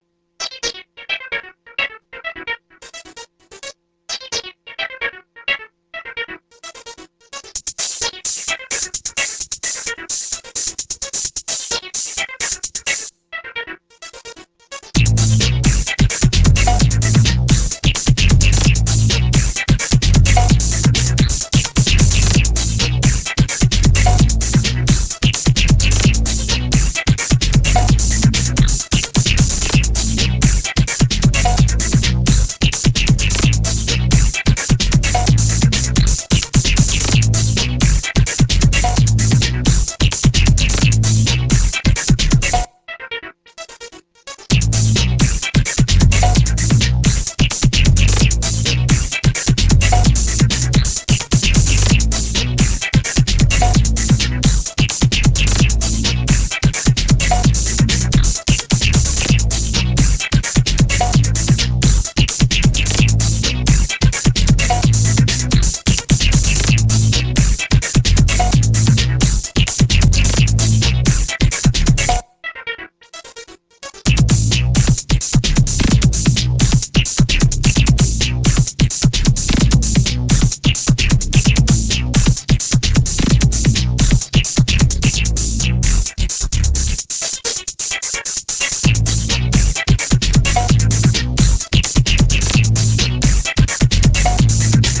Electropop